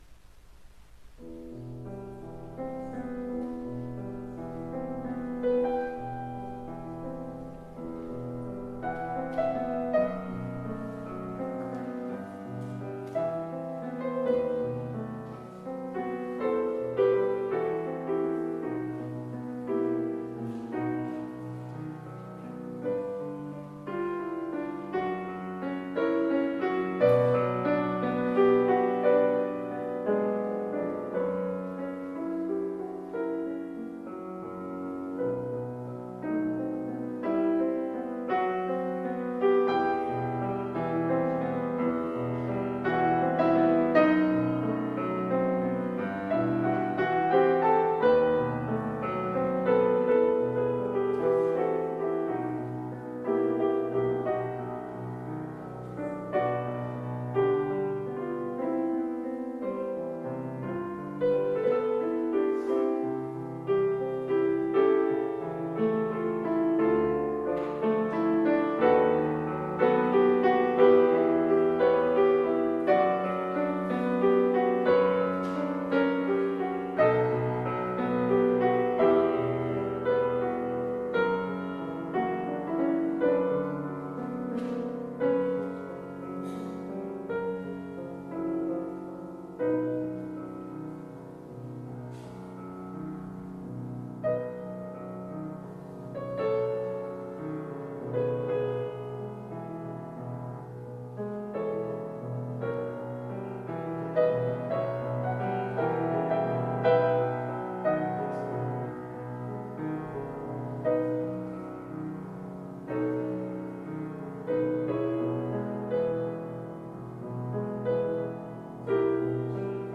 01 April 2026, Meditations on the Nocturnes of Tenebrae Audio recording of the 7pm service Service Bulletin: 26_04_01_Nine_Nocturnes_Tenbrae_FINAL Where’s the Music?